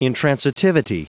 Prononciation du mot intransitivity en anglais (fichier audio)
Prononciation du mot : intransitivity